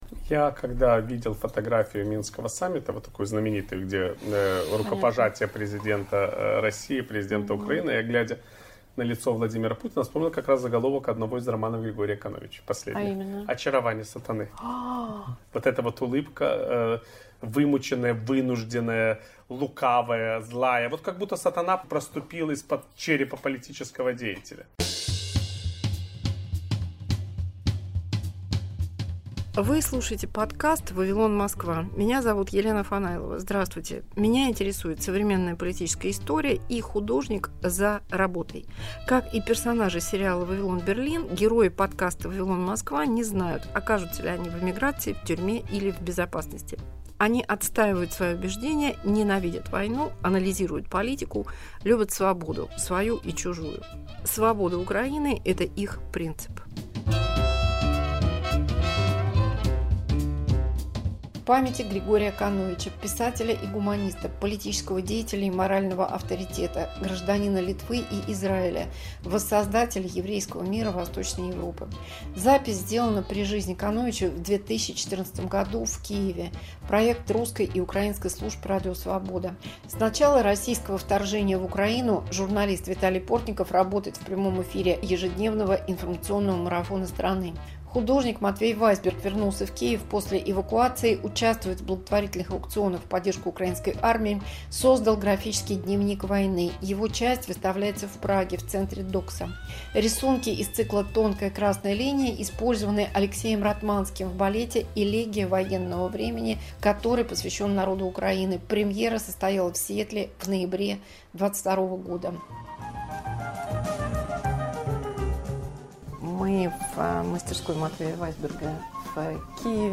Архивная запись 2015 в киевской мастерской Матвея Вайсберга